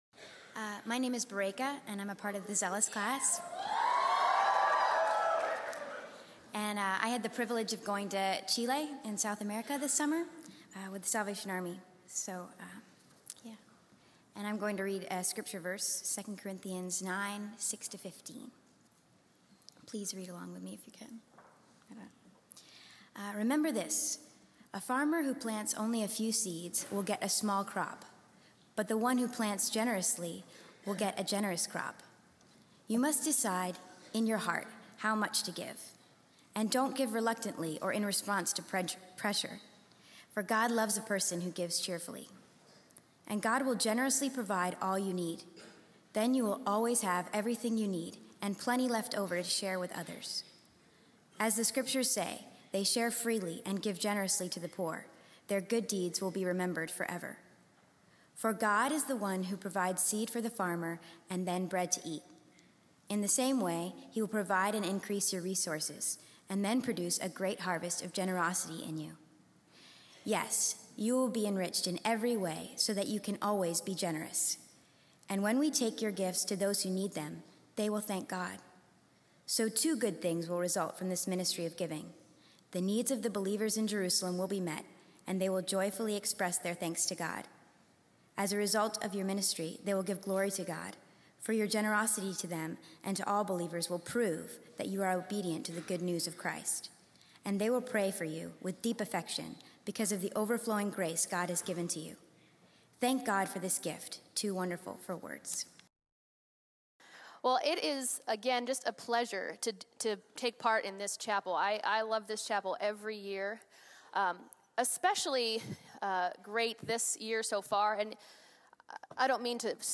Students speak about giving their summers to missions.